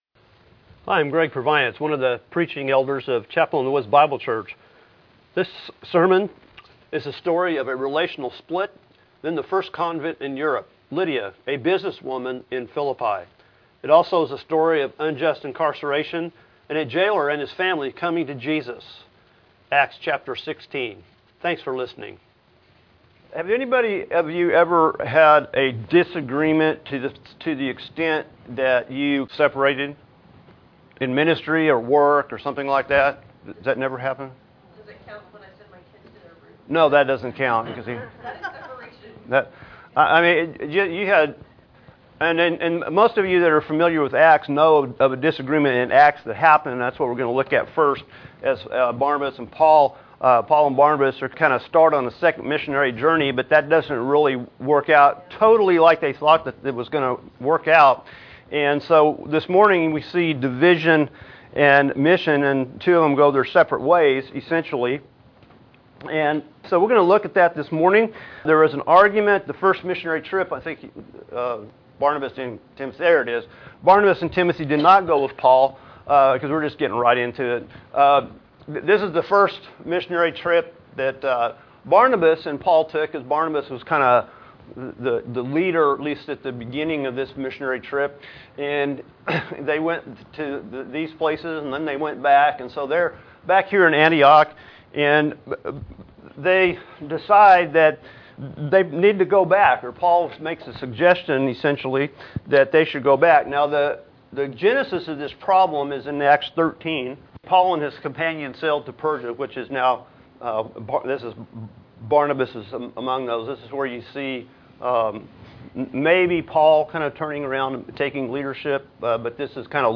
May 27, 2018 Acts 16 Trip to Europe MP3 SUBSCRIBE on iTunes(Podcast) Notes Discussion Sermons in this Series Paul and Silas split over a helper that didn't finish the task. Paul and Silas end up going to Europe and converting the first Christian in Europe, a businesswoman named Lydia.